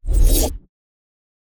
cast-generic-04.ogg